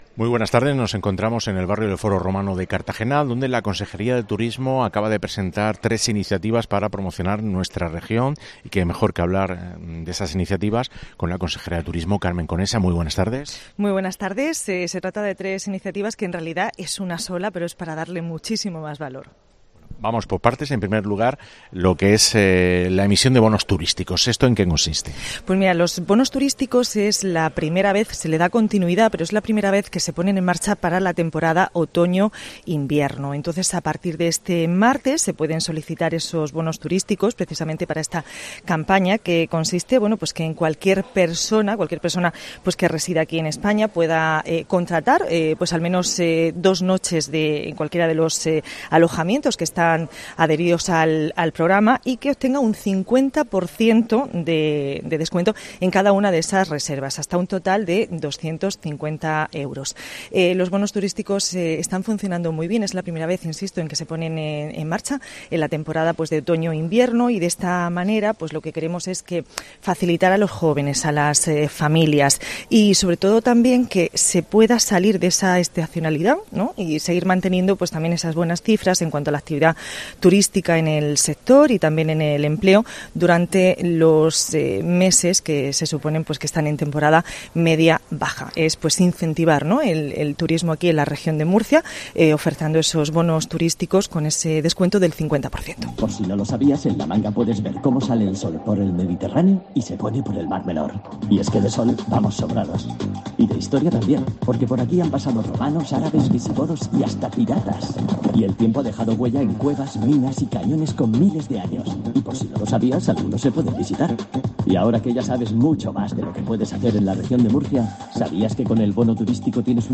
Entrevista con la Consejera de Turismo sobre la nueva campaña de bonos turísticos en la Región